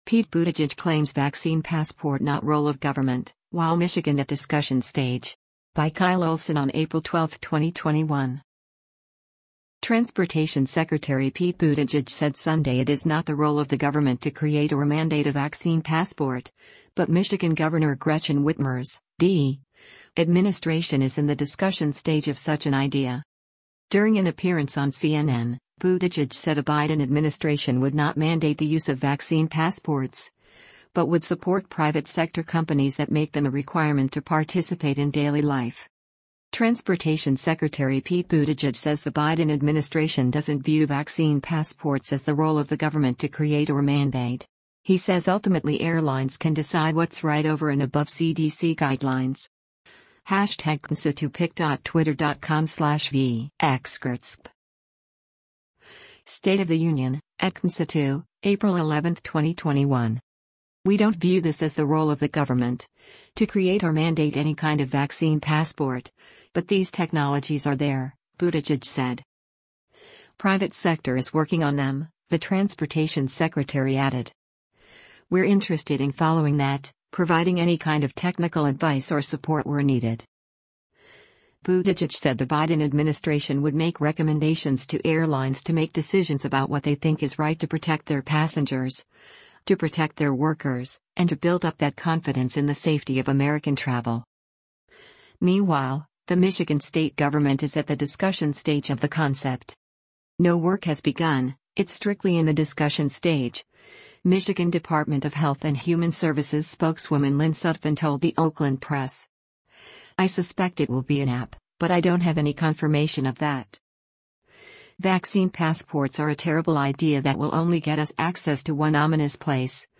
During an appearance on CNN, Buttigieg said a Biden administration would not mandate the use of vaccine passports, but would “support” private sector companies that make them a requirement to participate in daily life: